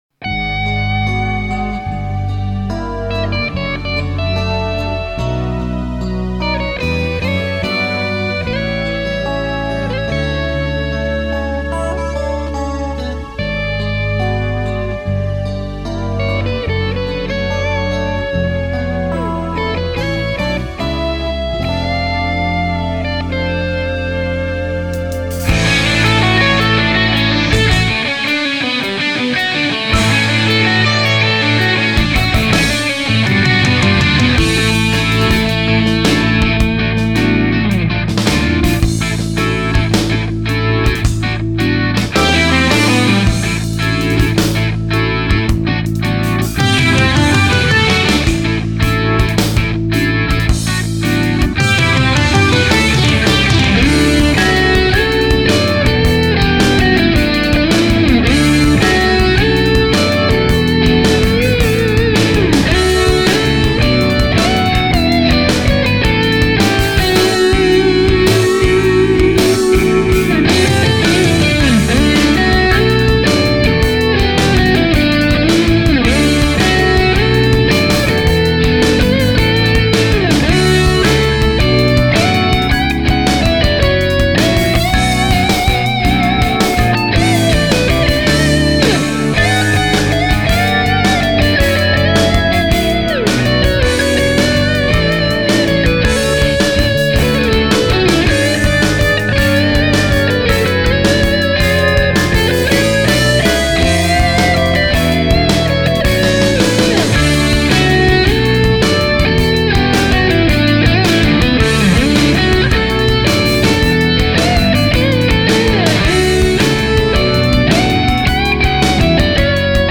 Instrumental
It’s a mixture of fusion and punk rock.